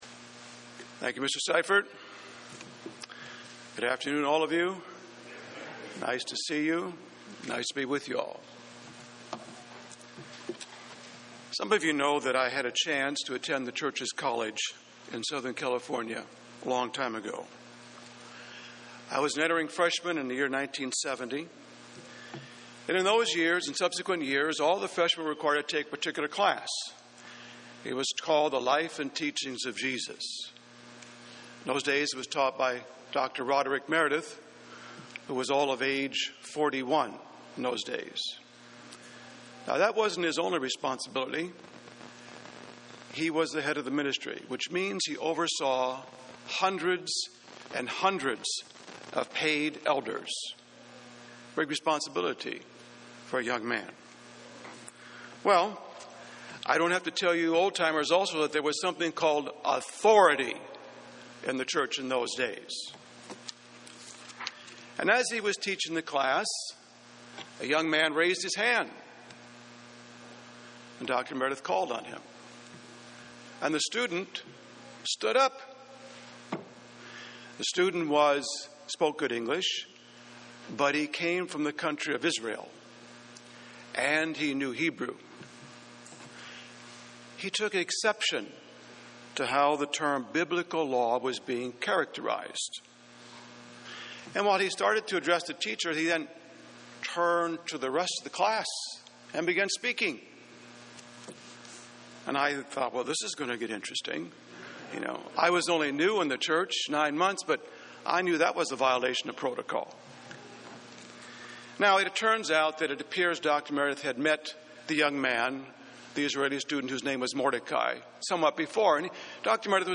UCG Sermon Studying the bible?
Given in Columbus, OH